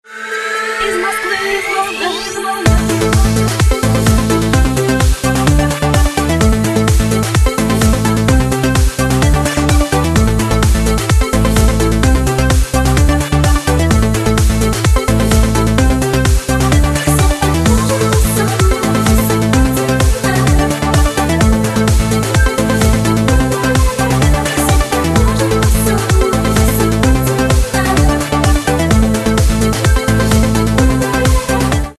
Жанр: Club